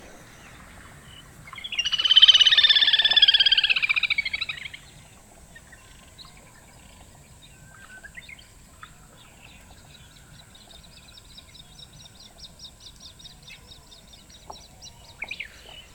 Mergullón pequeno
Canto